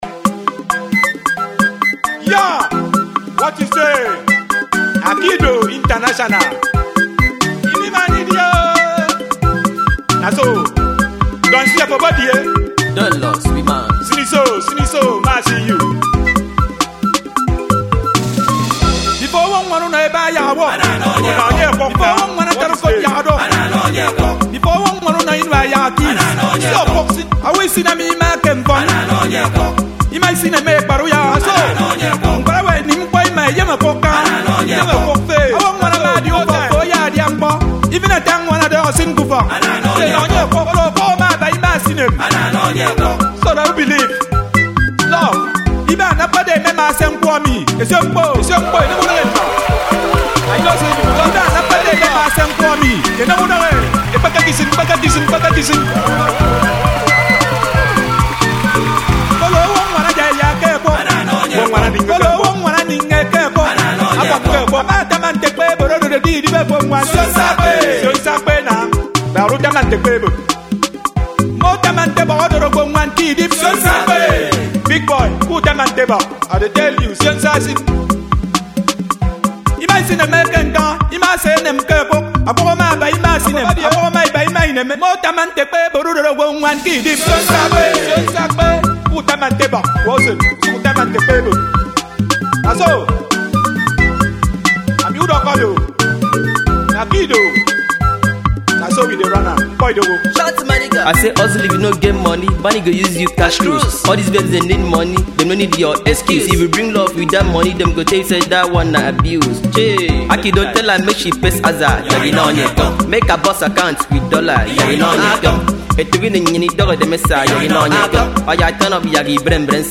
An indigenous Afro pop